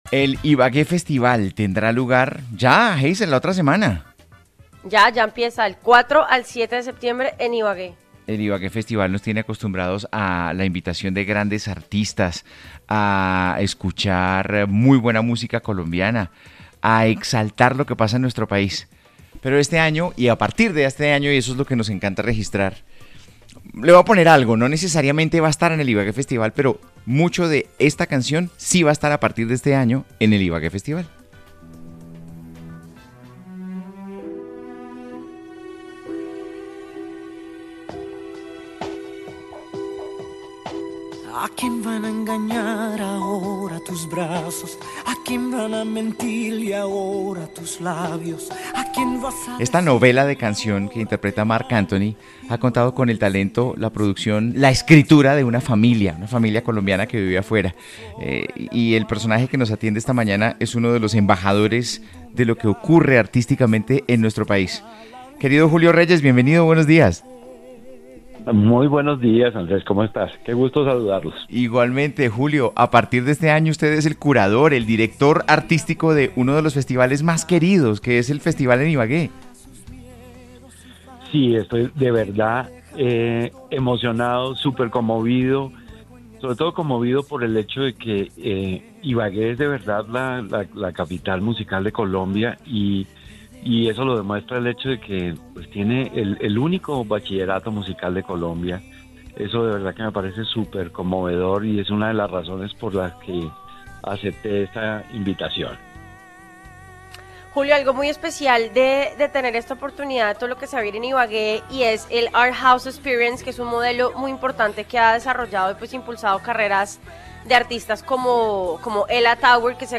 En diálogo con A vivir, Julio Reyes destacó que esta edición pondrá especial atención en los procesos creativos y en la profesionalización de los músicos, con el propósito de abrir caminos de formación y de circulación internacional para los talentos.